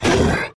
gnoll_warror_damage.wav